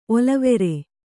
♪ olavere